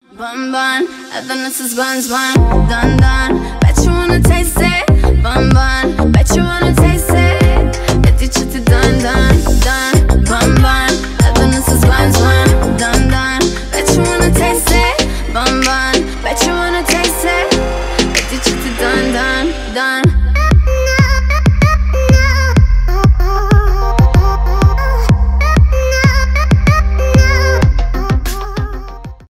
edm
dance pop , dancehall